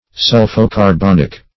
Search Result for " sulphocarbonic" : The Collaborative International Dictionary of English v.0.48: Sulphocarbonic \Sul`pho*car*bon"ic\, a. (Chem.)